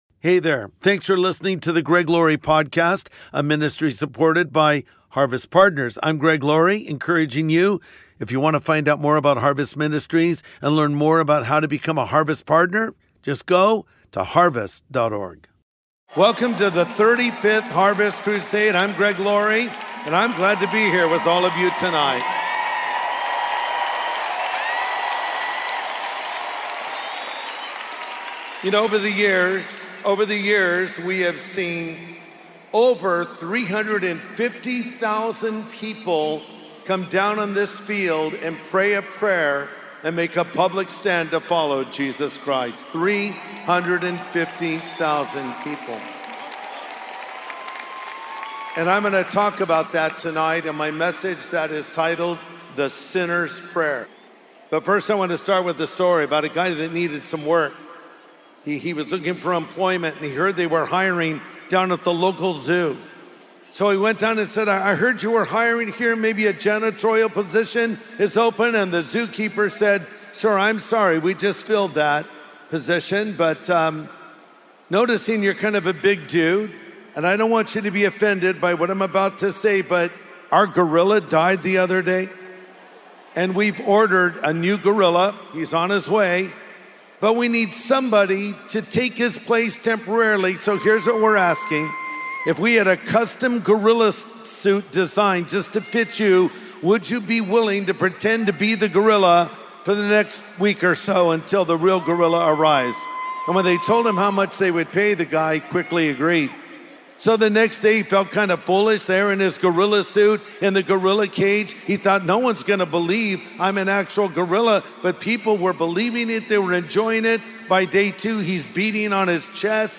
Pastor Greg Laurie shares a message titled, "The Sinner's Prayer," and invites the audience to begin their relationship with Jesus Christ at the 2024 Harvest Crusade.